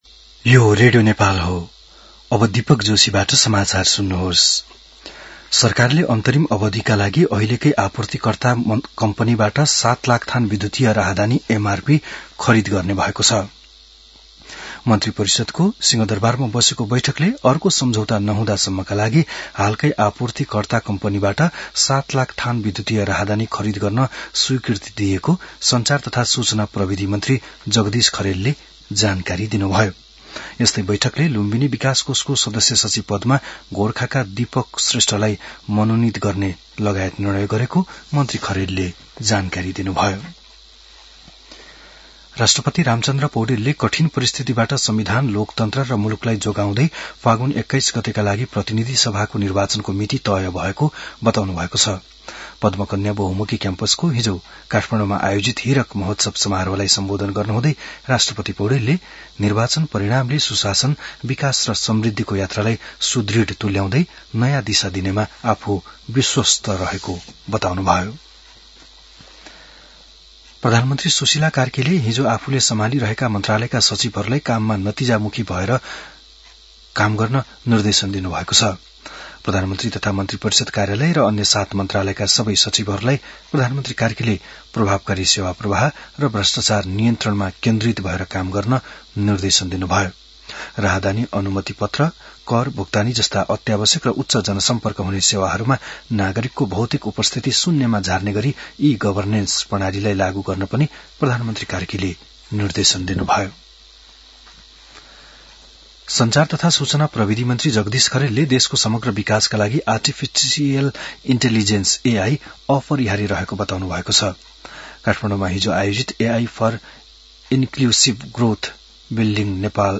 बिहान १० बजेको नेपाली समाचार : ६ मंसिर , २०८२